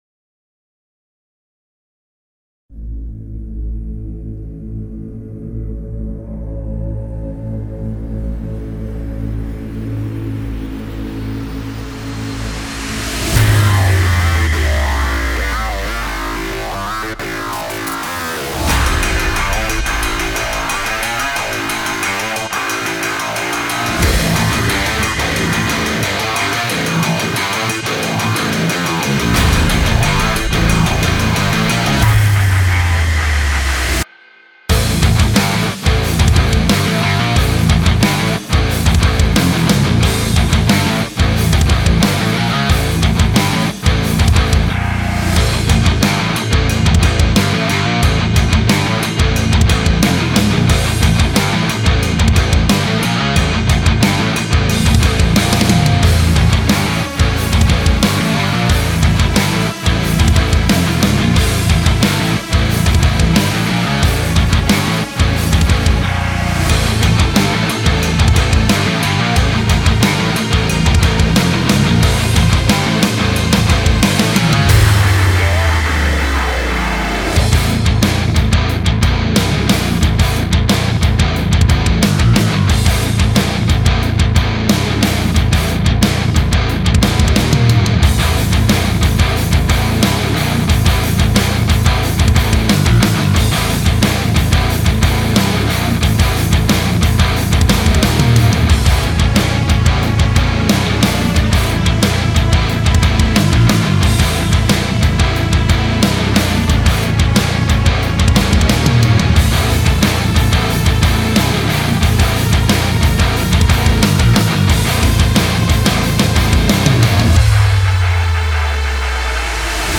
Как "подружить" электрогитары в дропнутых строях (drop A и ниже) и бас?
Убрал октавер снизу у ритм-гитар. Басухе навалил чутка дисторшн, вытащил ей мид и хаймид.
Бас стал прослушиваться и пробиваться сквозь остальное лучше.